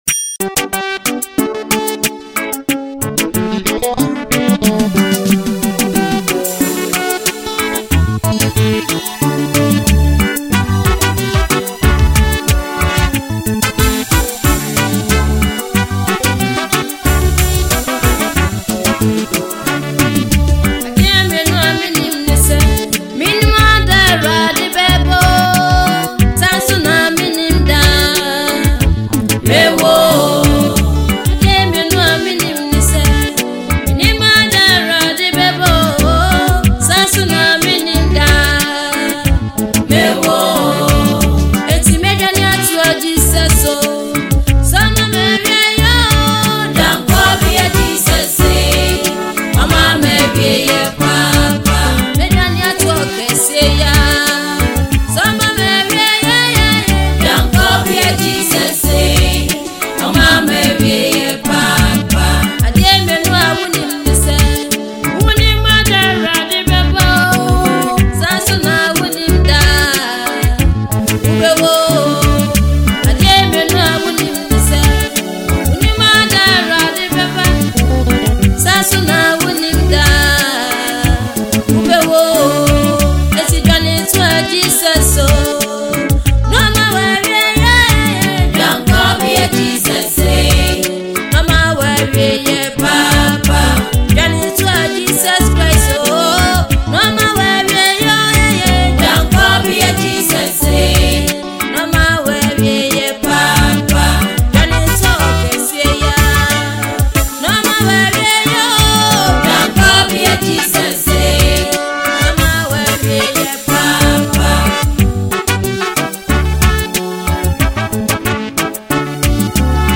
Ghanaian Gospel
This soulful track
With its catchy melody and heartfelt lyrics